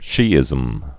(shēĭzəm)